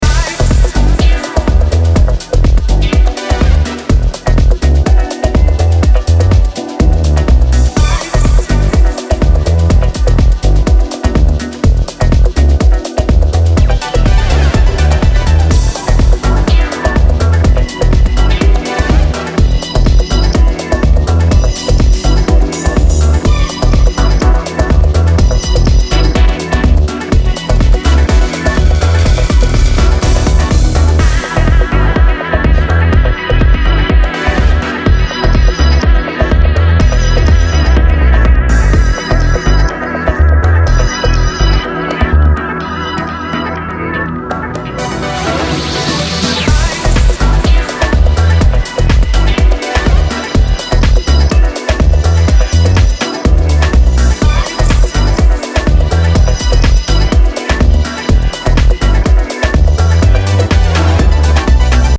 • Afro House, Funk House